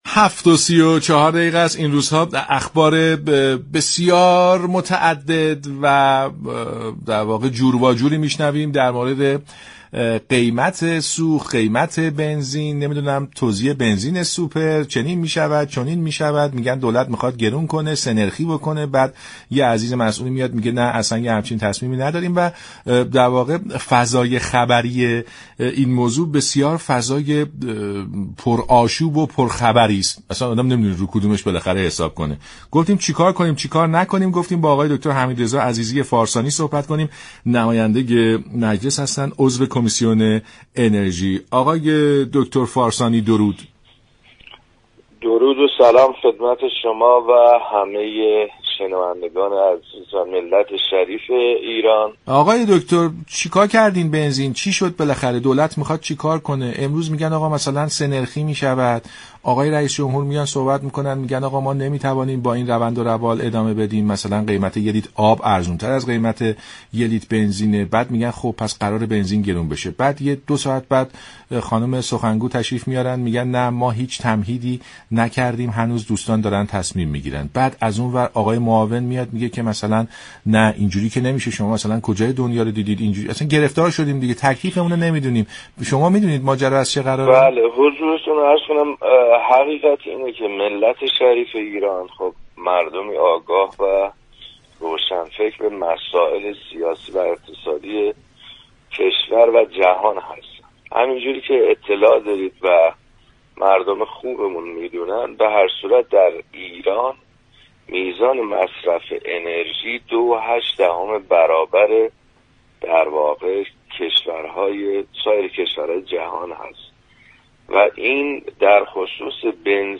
فارسانی عضو كمیسیون انرژی در برنامه سلام‌صبح‌بخیر گفت: در ایران هستند مردمی كه خودرو ندارند اما یارانه پنهان 47 هزار بنزین را پرداخت می‌كنند، این اتفاق نوعی بی‌عدالتی را در كشور ایجاد كرده است.